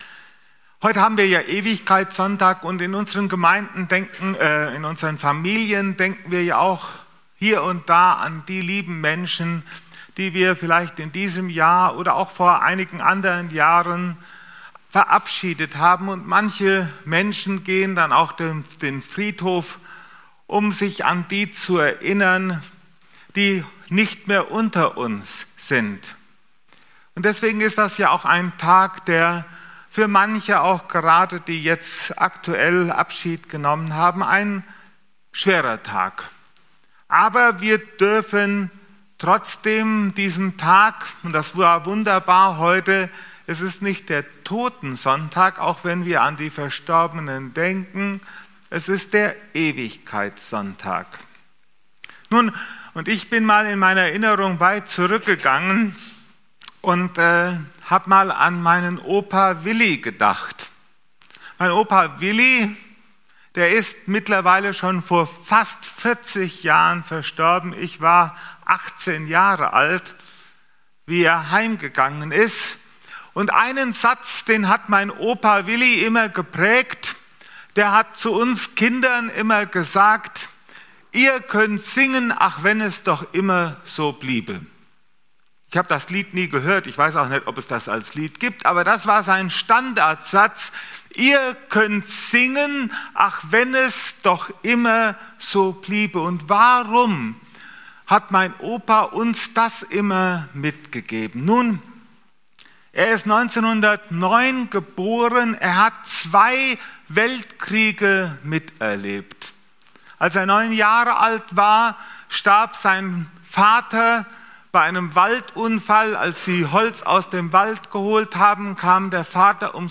23.11.2025 Ewigkeitssonntag ~ Predigten - FeG Steinbach Podcast